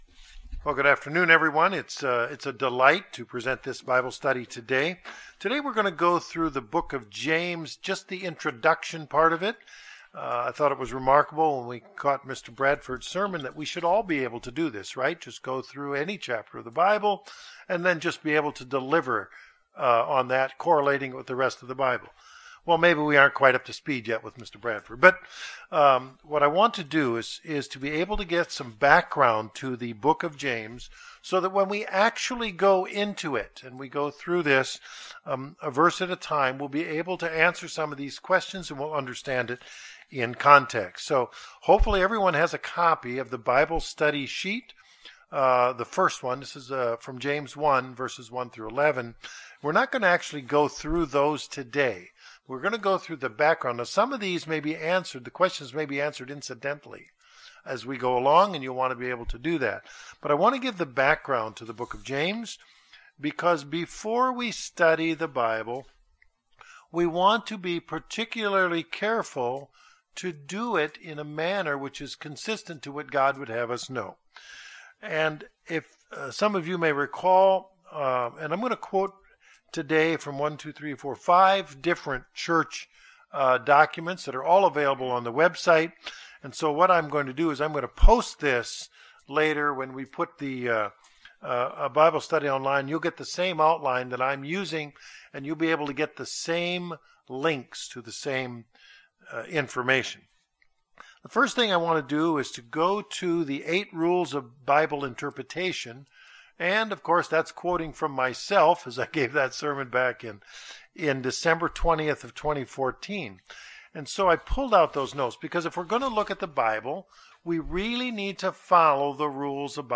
Bible Study – The Book of James – Introduction 4 June 2016 Cave Springs, AR